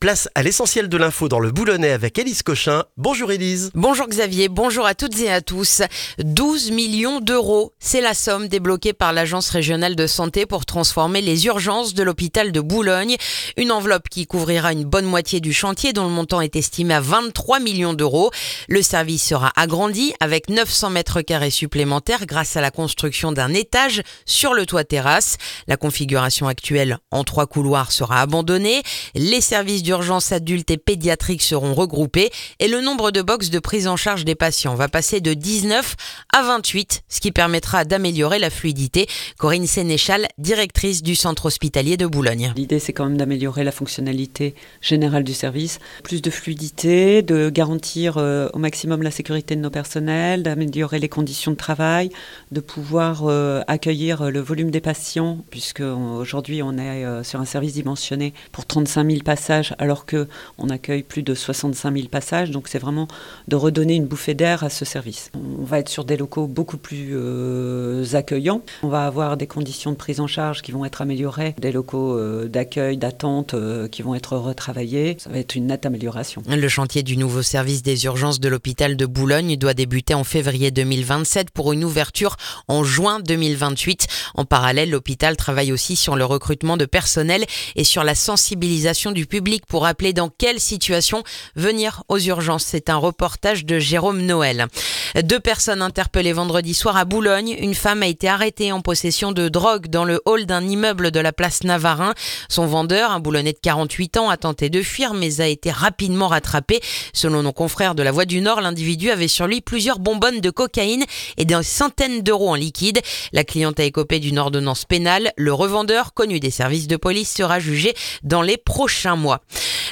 Le journal du mardi 9 décembre dans le boulonnais